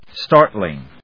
stár・tling